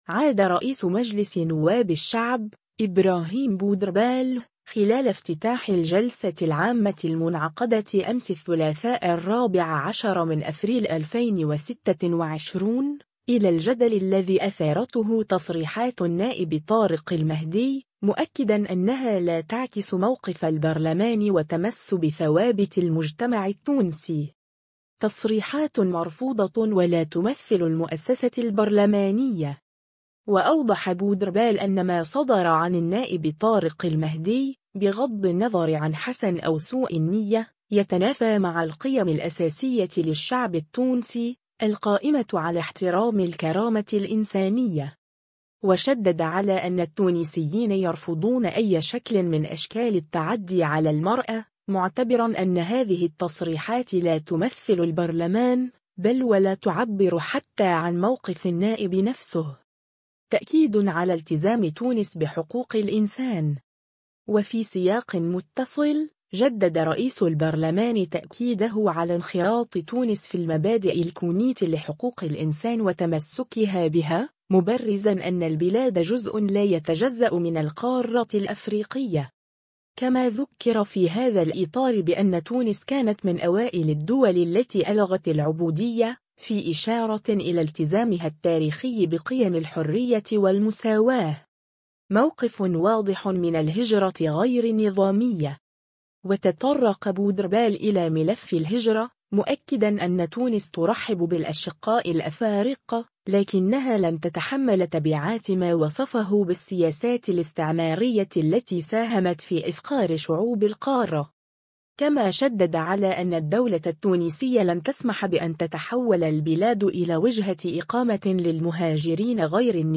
عاد رئيس مجلس نواب الشعب، إبراهيم بودربالة، خلال افتتاح الجلسة العامة المنعقدة أمس الثلاثاء 14 أفريل 2026، إلى الجدل الذي أثارته تصريحات النائب طارق المهدي، مؤكداً أنها لا تعكس موقف البرلمان وتمسّ بثوابت المجتمع التونسي.